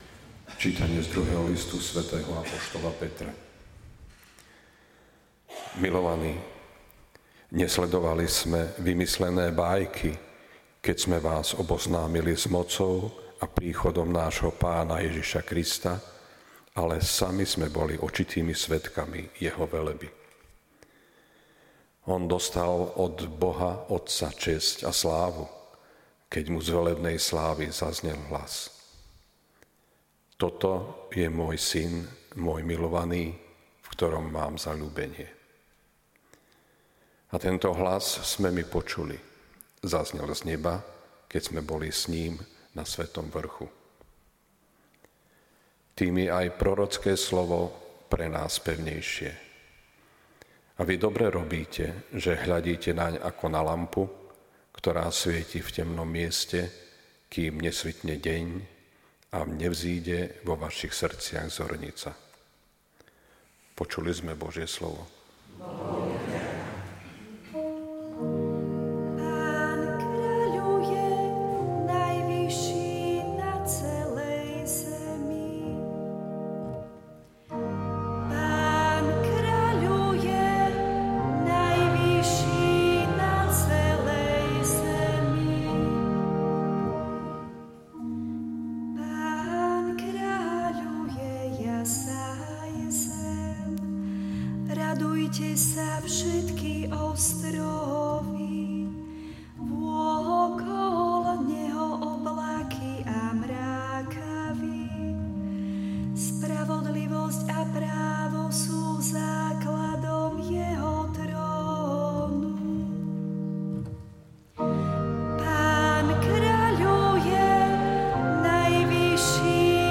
LITURGICKÉ ČÍTANIA | 06. augusta 2025